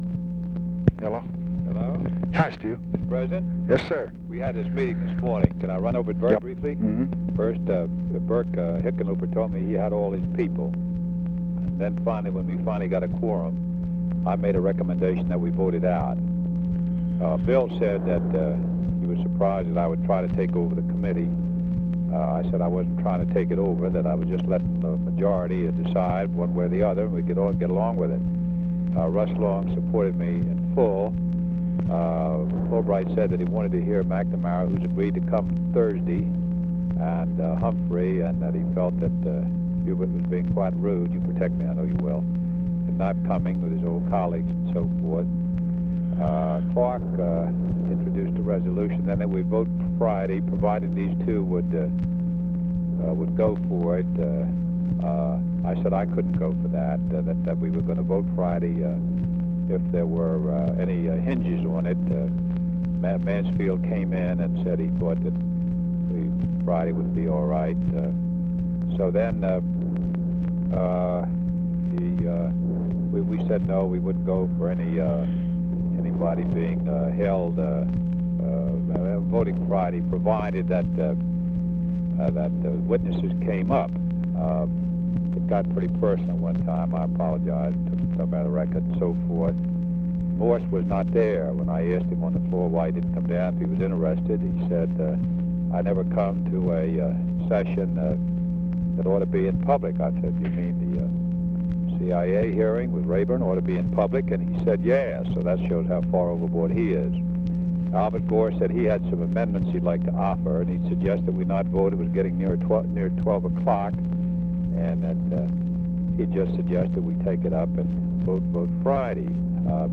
Conversation with STUART SYMINGTON, February 28, 1966
Secret White House Tapes